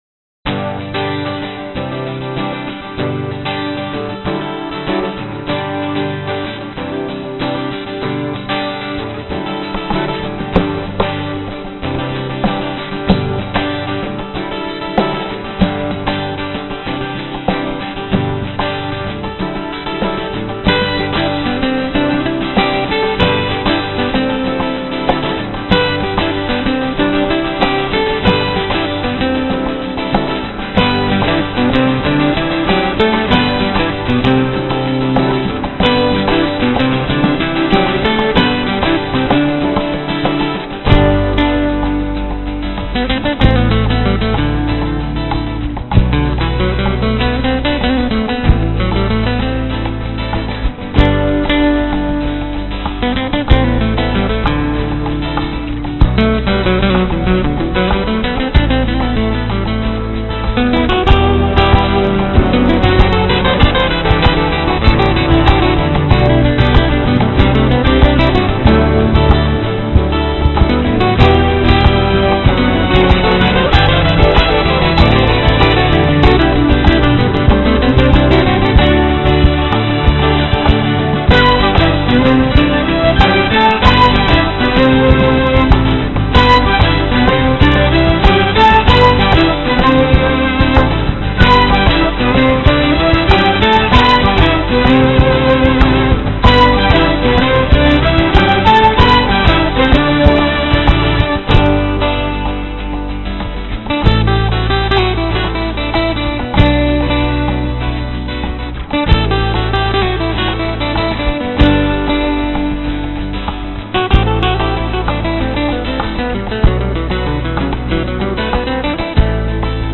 Basso, Basso fretless, Flauto, Voci, Armonizzazioni vocali
Flauto traverso
Pianoforte
Violino, Voci, Effetto acqua, Armonizzazioni vocali
Violino, Viola, Violoncello